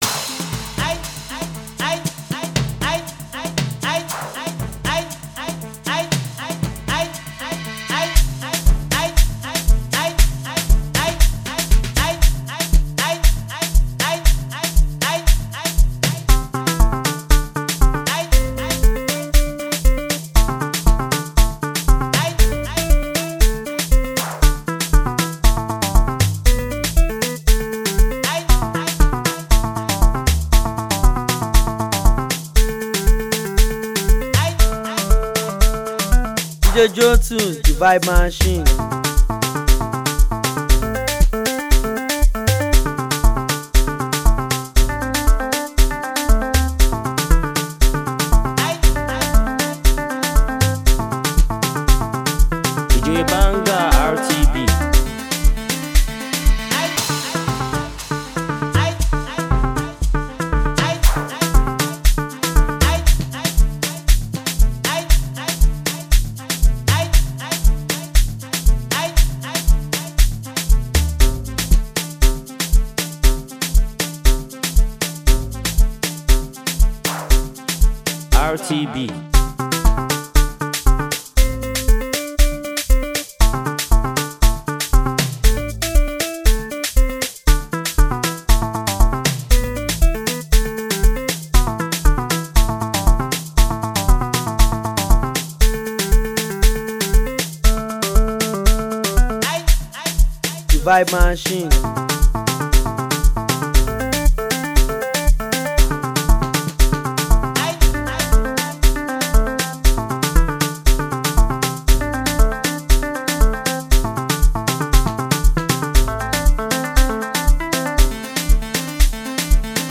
dance track